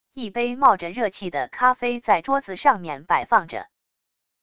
一杯冒着热气的咖啡在桌子上面摆放着wav音频